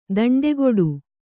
♪ daṇḍegoḍu